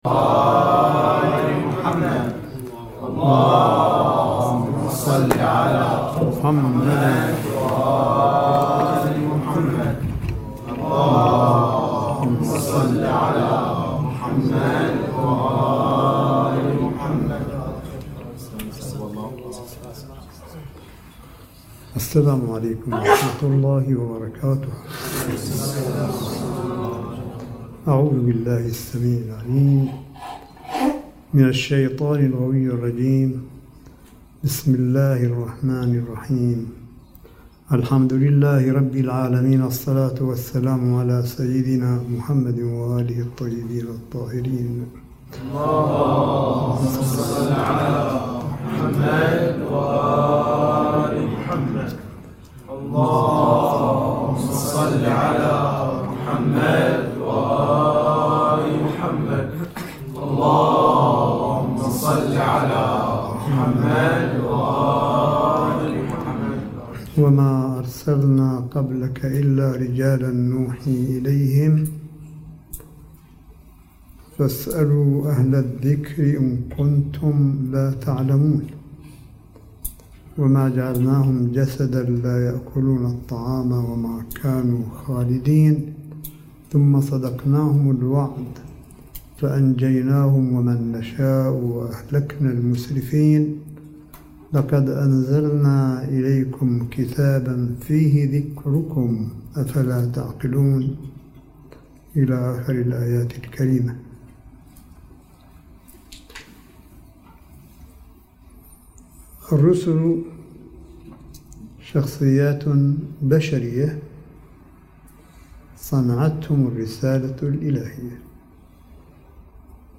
ملف صوتي للحديث القرآني لسماحة آية الله الشيخ عيسى أحمد قاسم حفظه الله بقم المقدسة – 8 شهر رمضان 1440 هـ / 14 مايو 2019م